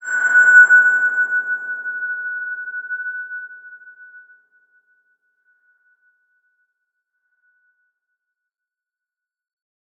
X_BasicBells-F#4-mf.wav